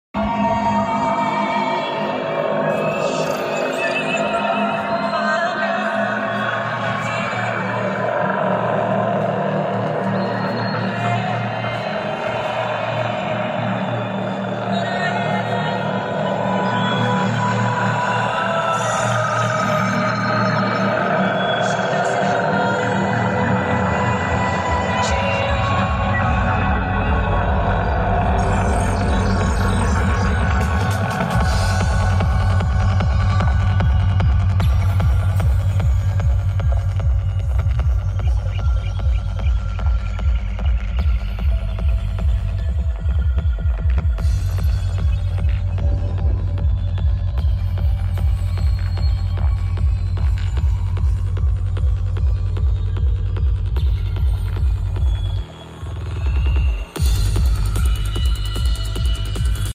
the April 2022 Danley Sound Labs Rockingham NC roadshow: Jericho8 top premier with Boundary Coupled subwoofer demo